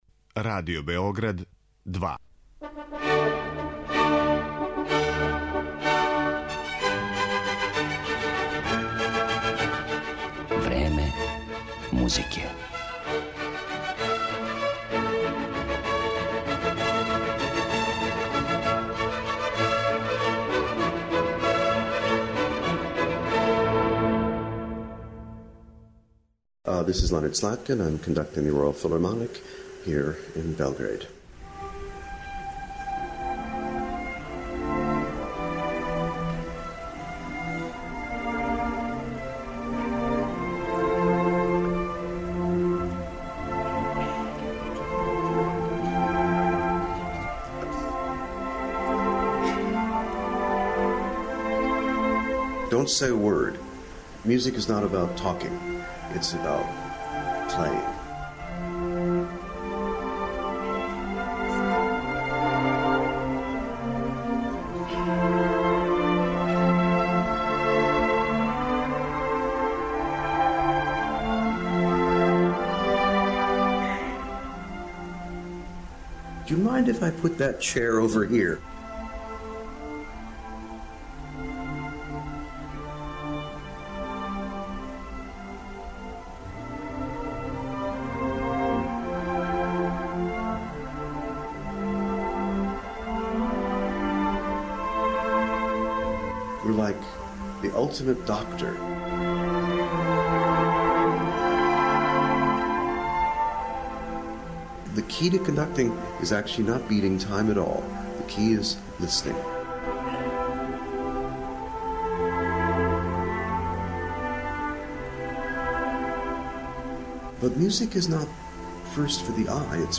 Данашња емисија Време музике је посвећена једном од највећих диригената нашег доба, Ленарду Слаткину који је у каријери дугој скоро пола века, руководио многим врхунским америчким али и европским ансамблима. Године 2007. је са Краљевским филхармонијским оркестром из Лондона гостовао и у Београду и том приликом снимљен је и интервју са овим великим америчким музичарем који ћете моћи да чујете у емисији.